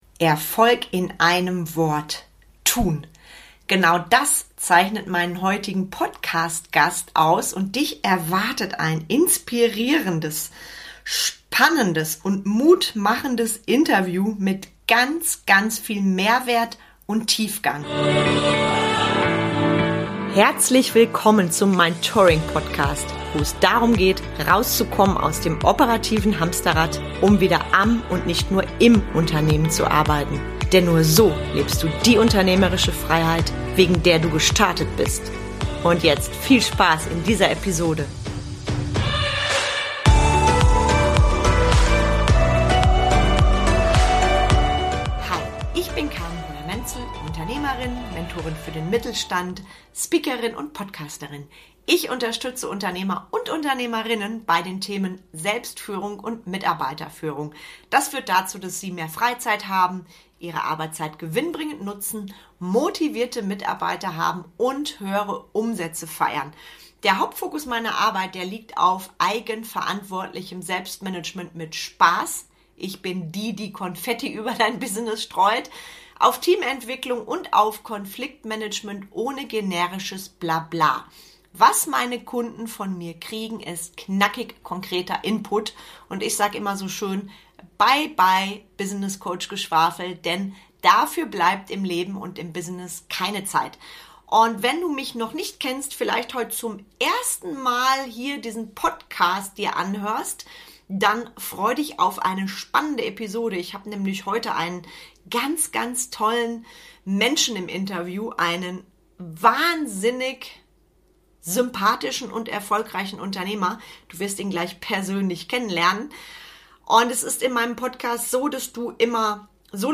Herausgekommen ist ein radikal ehrliches Podcastinterview mit ganz viel Tiefgang. Diese Folge ist eine echte Mutmachfolge für jeden Unternehmer.